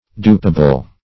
Search Result for " dupable" : The Collaborative International Dictionary of English v.0.48: Dupable \Dup"a*ble\, a. Capable of being duped.